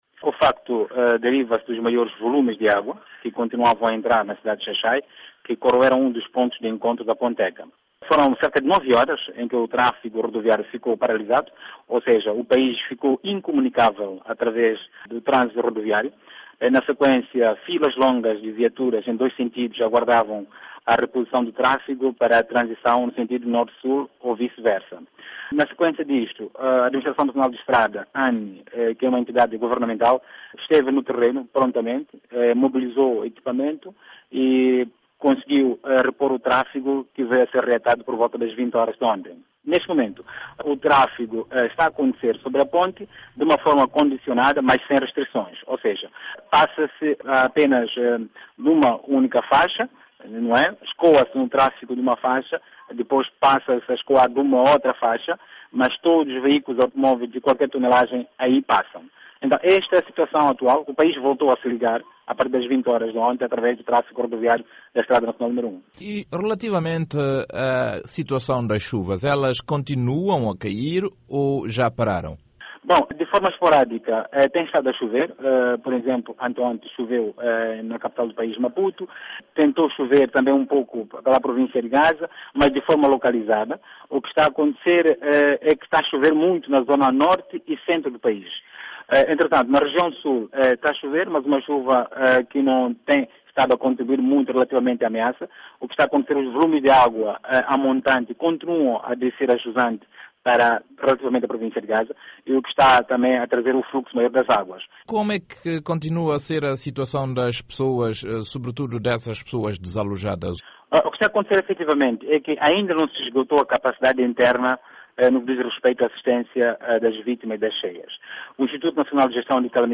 Entevista